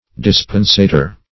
Dispensator \Dis"pen*sa`tor\